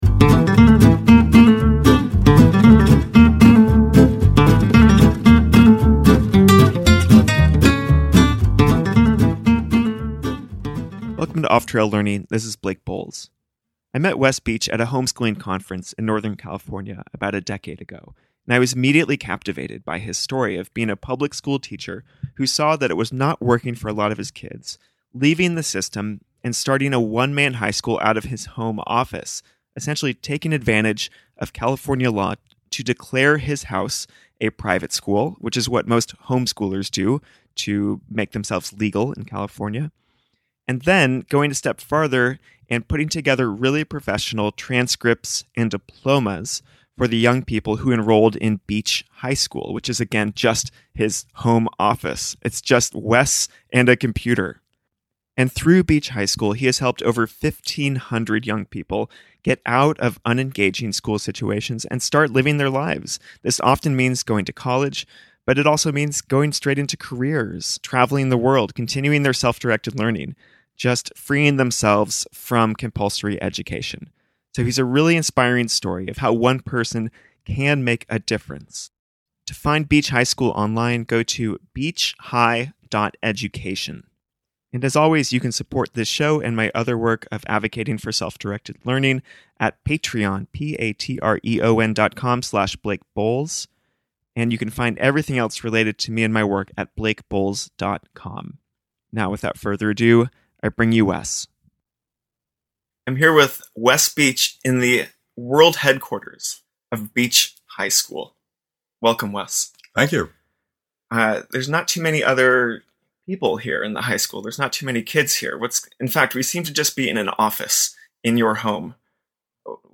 recorded our interview in his home office